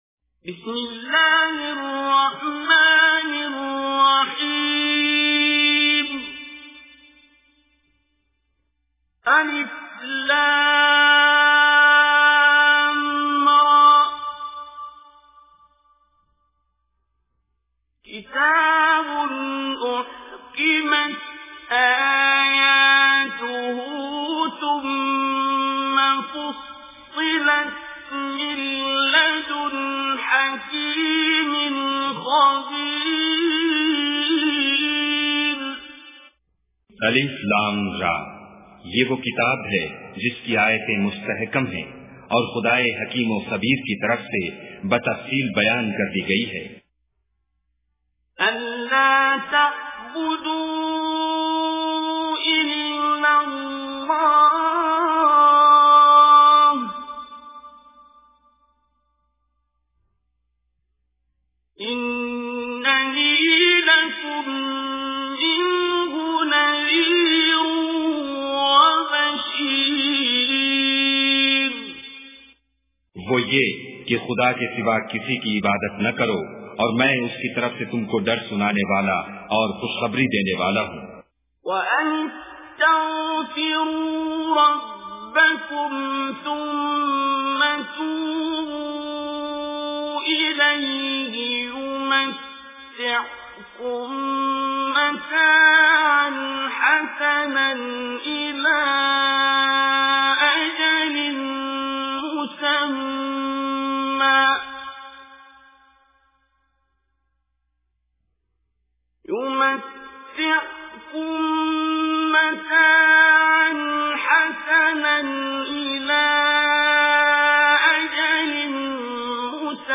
Listen online and download mp3 free urdu translation and tilawat of Surah Hud.